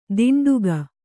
♪ diṇḍuga